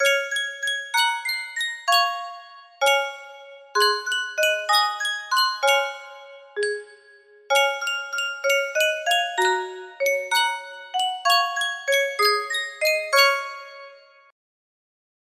Sankyo Music Box - Whispering Hope NBC music box melody
Full range 60